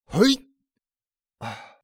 XS瞬间起身2.wav
XS瞬间起身2.wav 0:00.00 0:01.84 XS瞬间起身2.wav WAV · 159 KB · 單聲道 (1ch) 下载文件 本站所有音效均采用 CC0 授权 ，可免费用于商业与个人项目，无需署名。
人声采集素材